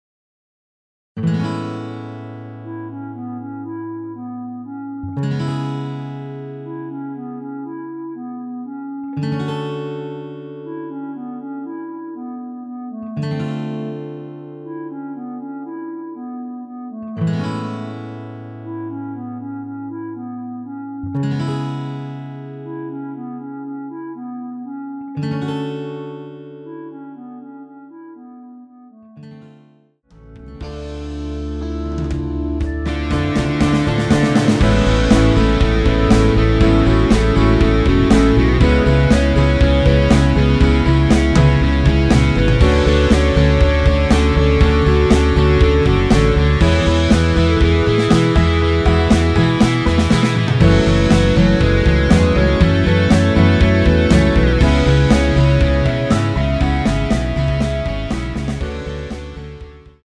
원키에서(-2)내린 멜로디 포함된 MR입니다.
앞부분30초, 뒷부분30초씩 편집해서 올려 드리고 있습니다.
(멜로디 MR)은 가이드 멜로디가 포함된 MR 입니다.